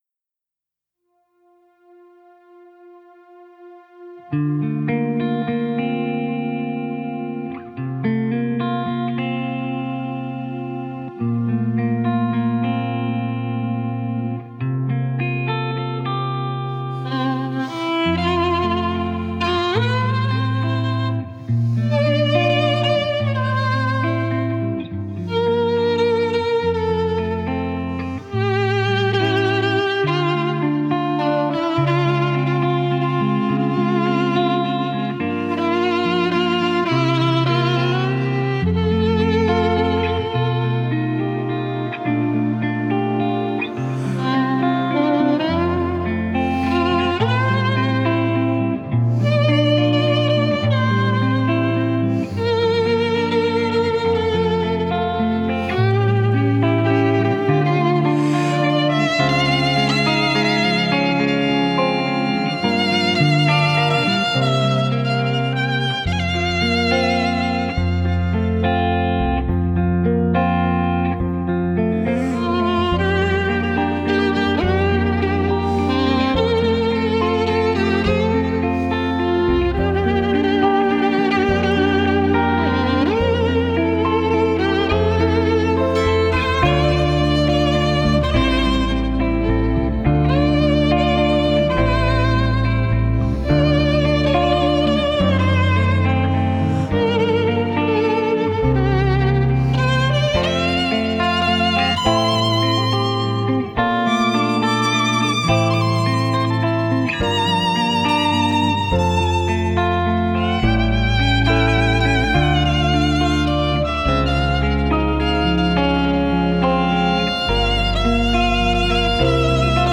Genre: Score
virtuoses Spiel mit modernen Klängen